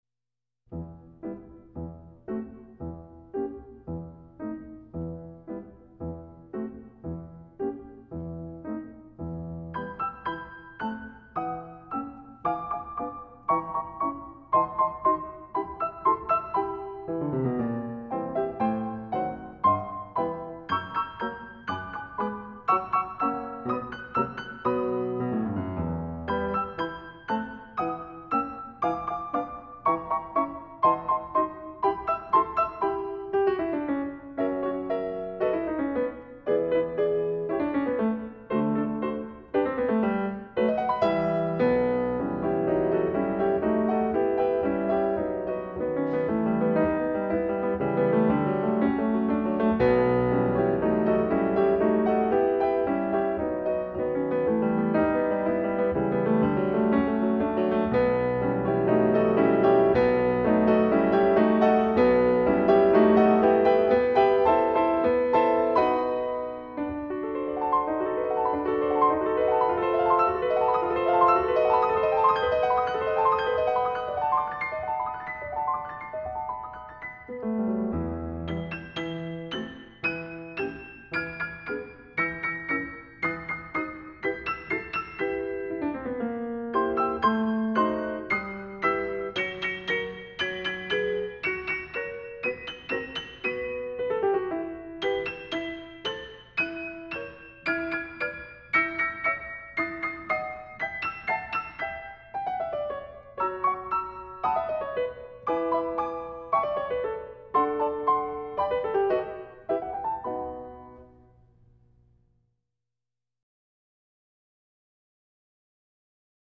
歌曲风格：轻音乐 (Easy Listening) / 纯音乐 (Pure Music)
以钢琴演奏四部剧目 胡桃钳、皮尔金、天使组曲、崔斯坦与伊索德